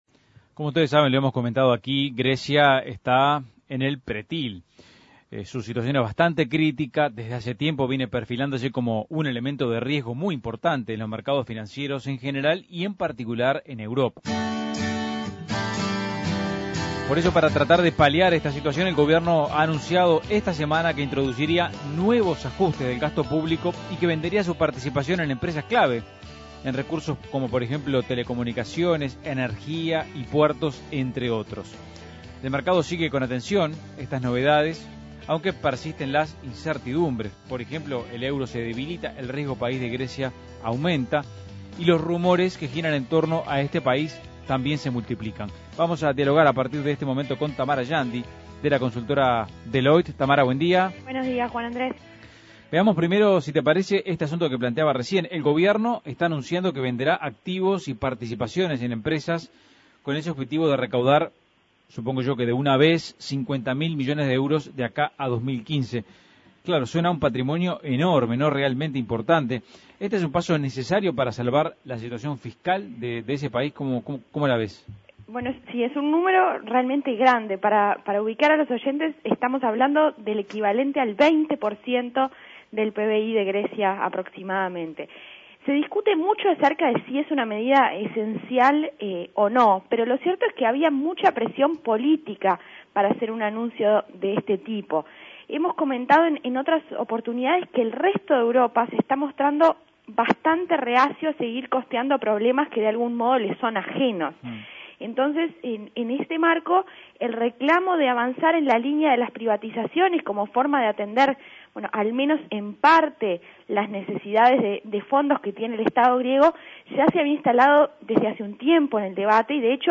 Análisis Económico ¿Cómo queda el panorama fiscal de Grecia tras los anuncios de privatizaciones y nuevos ajustes del gasto público?